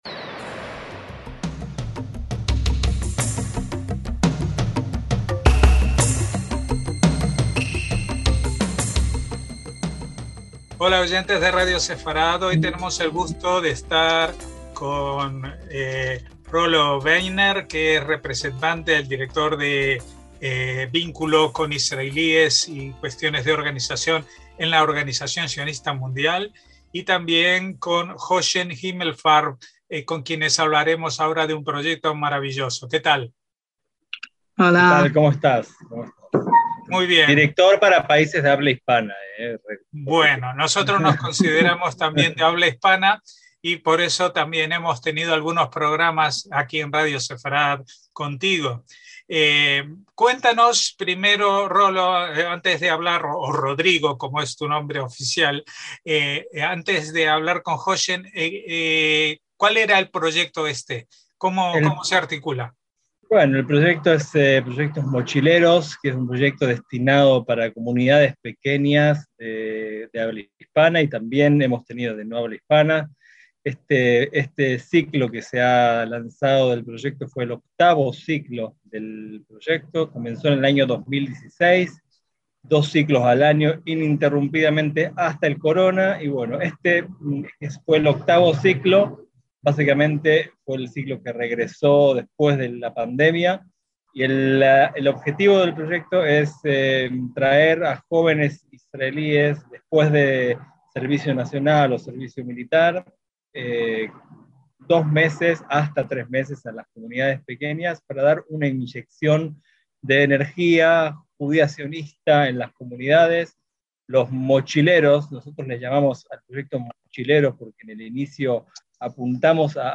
Nos reunimos con ambos para que compartiesen con nuestros oyentes tal experiencia, que esperamos sirva de incentivo para otros jóvenes israelíes.